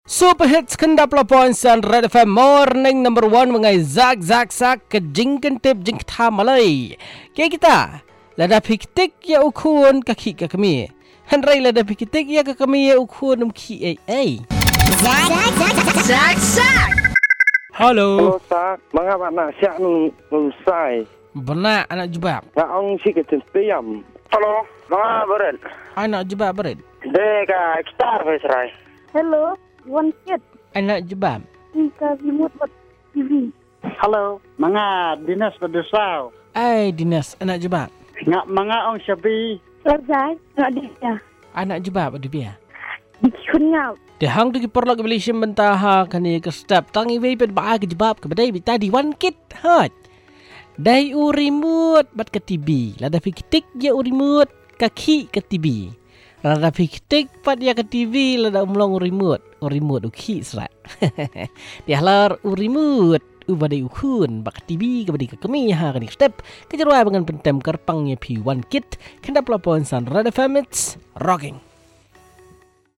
calls and results